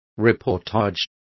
Complete with pronunciation of the translation of reportage.